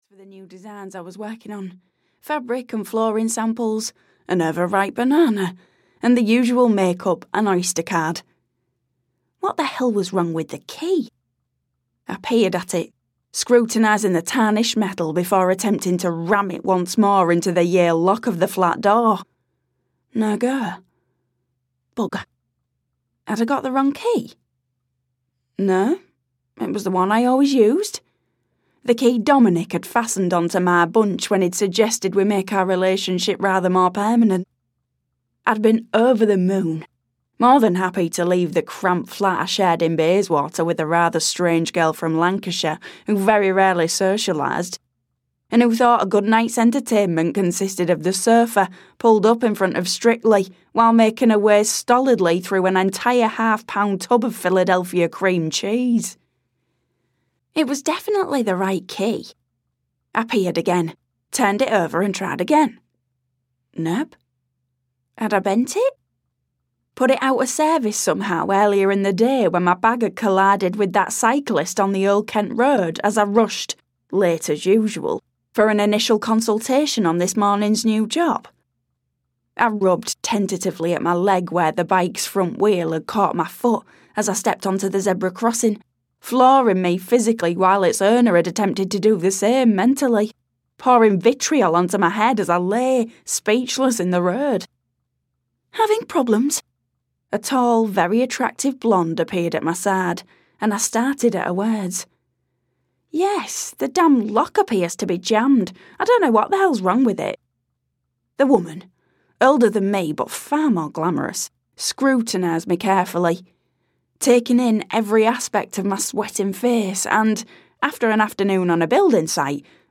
Coming Home to Holly Close Farm (EN) audiokniha
Ukázka z knihy